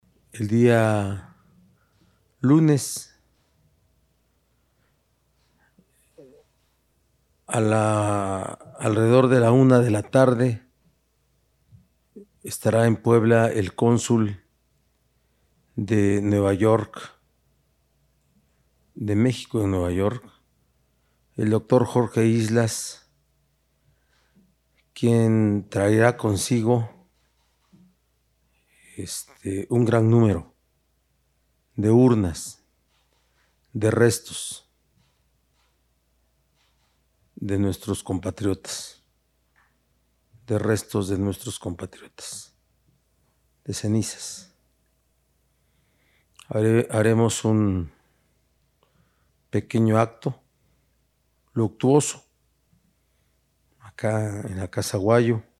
En videoconferencia de prensa, Barbosa Huerta informó que se llevará a cabo un evento en Casa Aguayo, en memoria de las y los poblanos fallecidos en la Unión Americana.